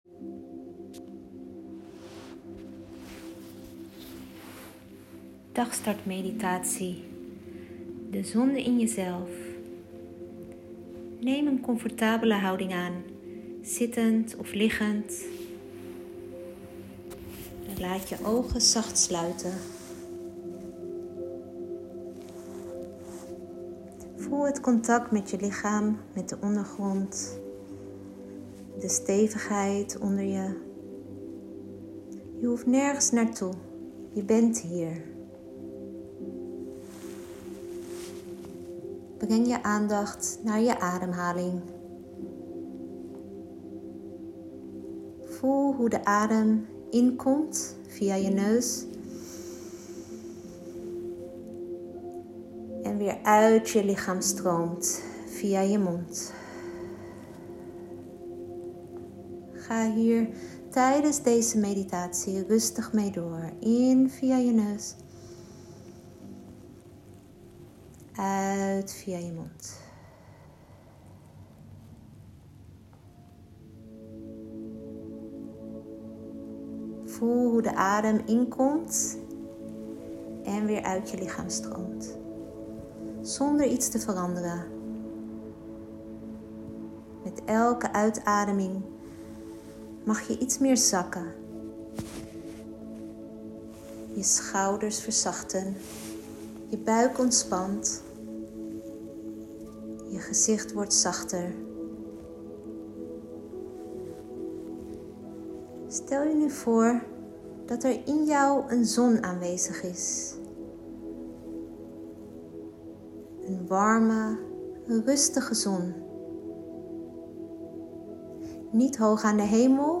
Het enige wat je hoeft te doen, is mijn stem volgen en je overgeven aan de meditatie of ademhalingsoefening.